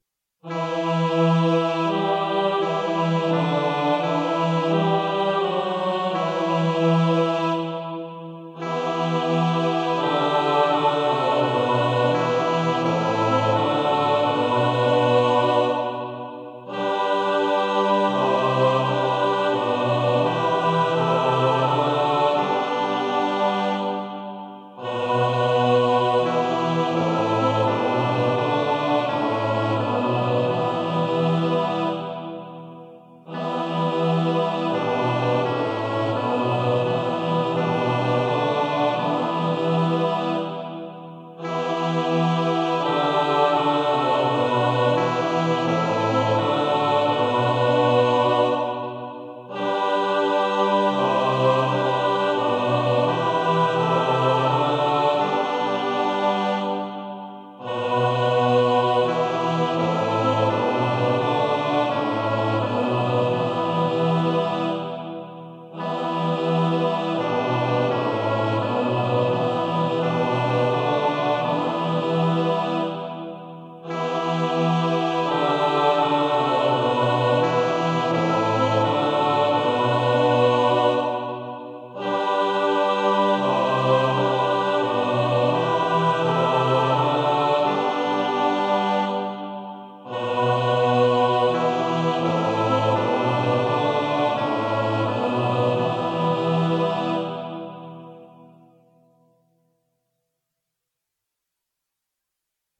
SATB
pro smíšený sbor